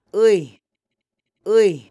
Diphthongs are most easily explained as instances where one vowel glides into a different vowel.
eigooey